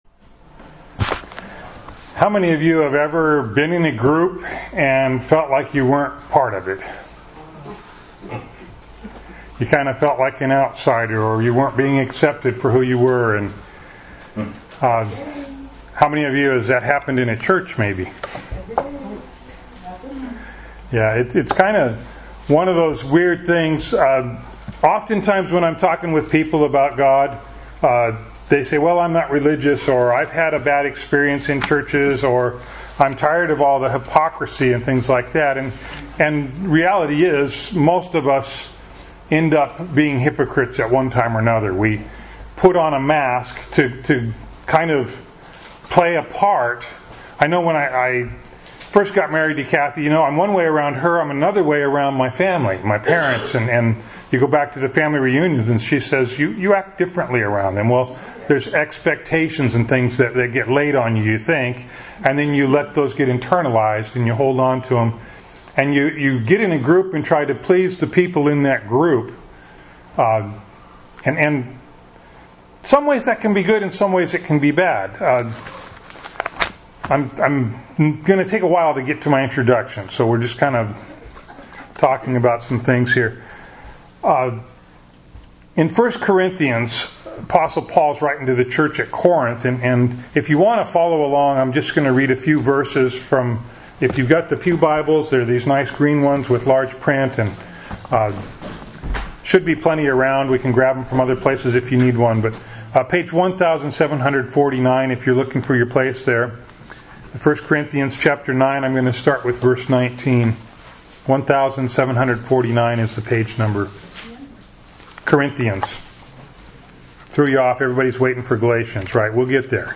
Bible Text: Galatians 2:11-16 | Preacher